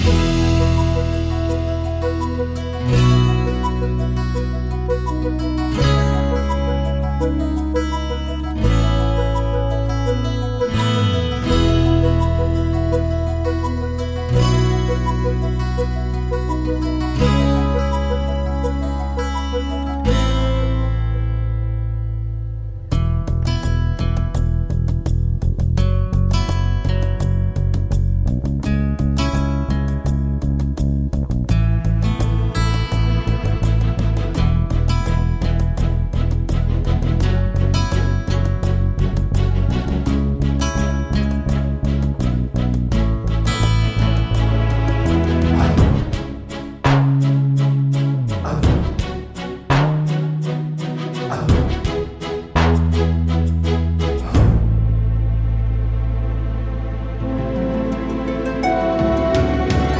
With Intro Clicks Soundtracks 2:44 Buy £1.50